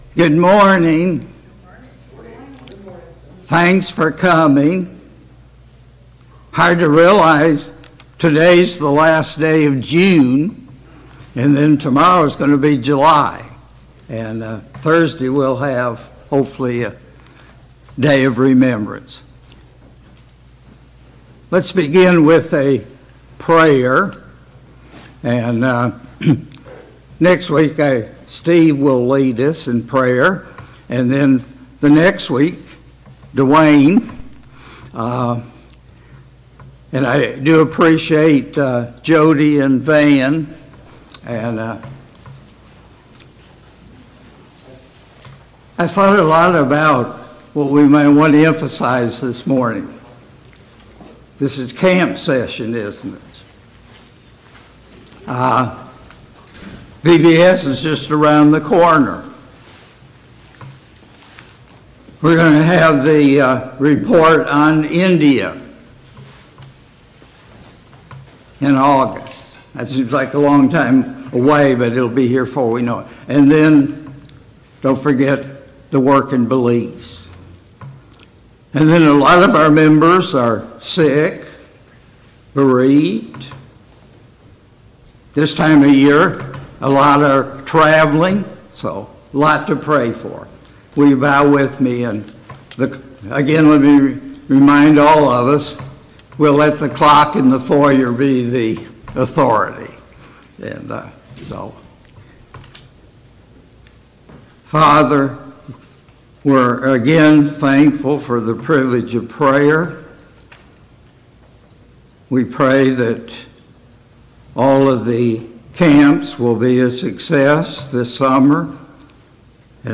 A Study of 2 Corinthians Passage: 2 Corinthians 5 Service Type: Sunday Morning Bible Class « 13.